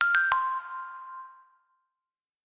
Received_Signal.ogg